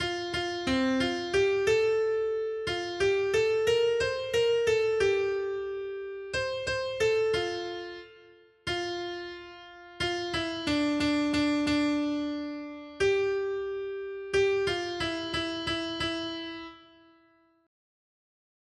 Noty Štítky, zpěvníky ol35.pdf responsoriální žalm Žaltář (Olejník) 35 Ž 89, 4-5 Ž 89, 16-17 Ž 89, 27 Ž 89, 29 Skrýt akordy R: Na věky budu zpívat o tvém milosrdenství, Hospodine. 1.